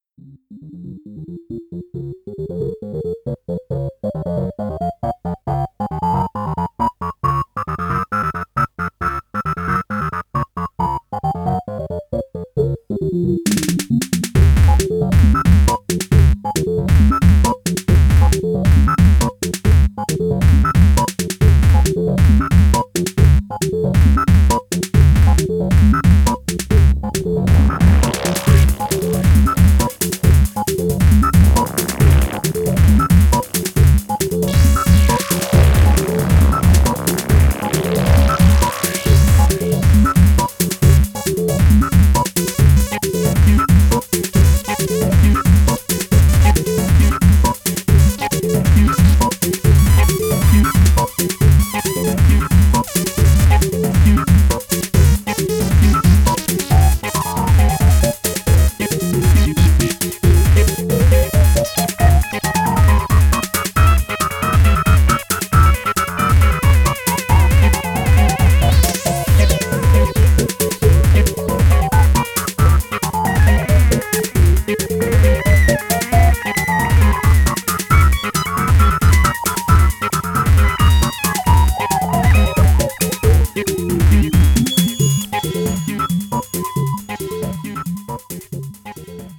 ポスト・パンキッシュ・レイヴ！